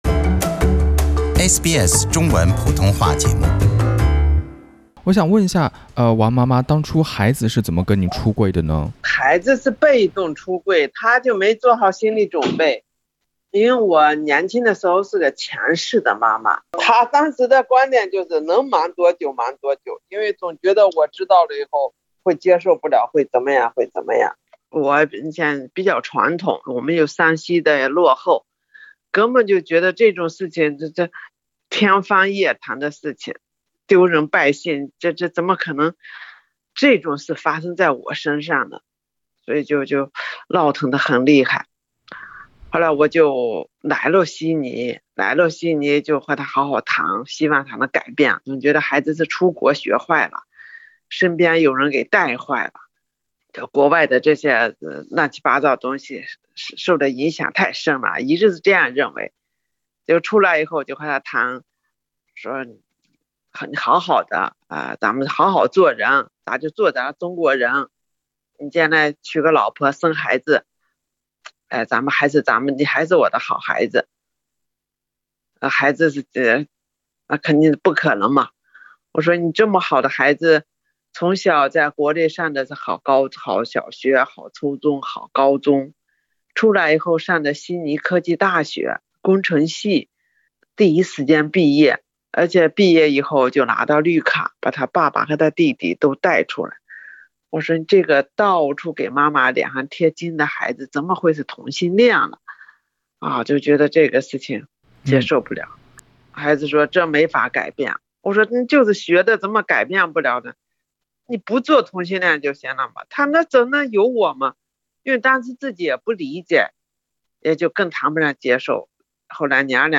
我儿子的同性婚姻：华人妈妈亲述孩子的出柜经历